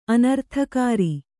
♪ anarthakāri